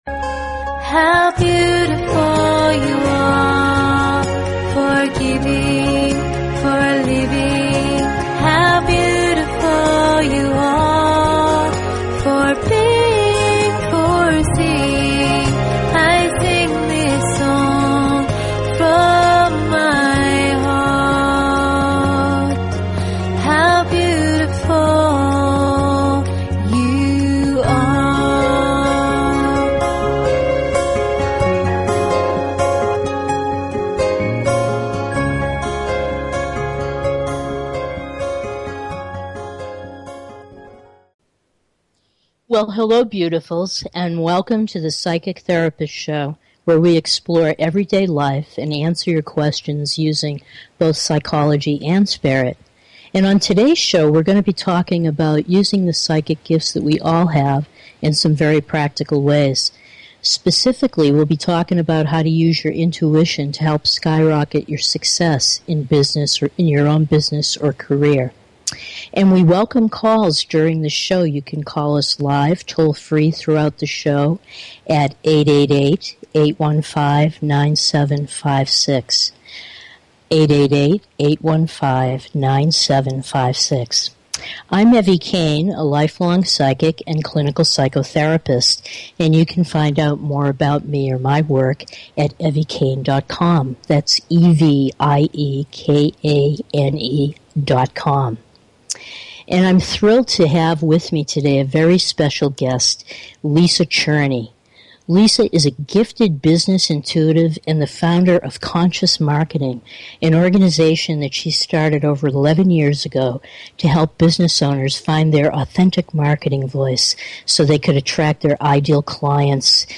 Talk Show Episode, Audio Podcast, Psychic_Therapist_Show and Courtesy of BBS Radio on , show guests , about , categorized as